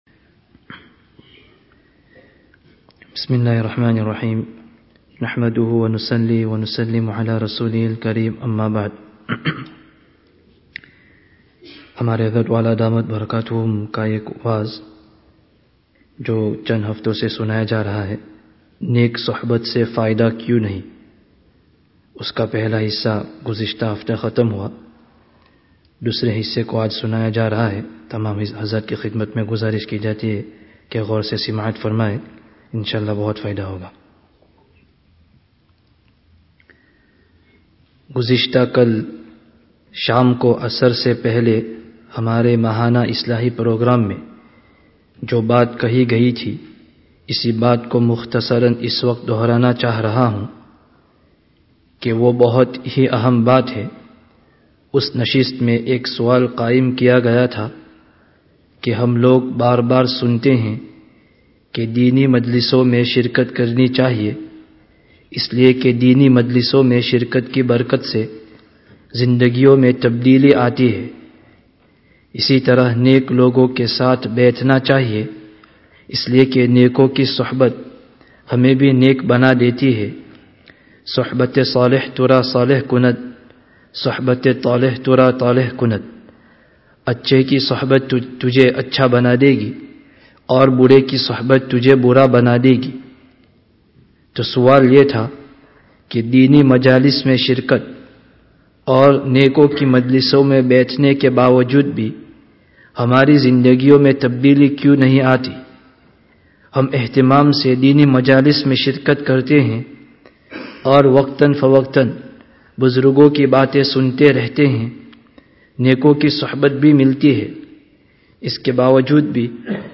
Download Urdu 2013 friday tazkiyah gathering wa'z bil-kitab Related articles Wa'z Bil-Kitāb: Neyk Suhbat se Fā'idah kyu(n) nahi(n)?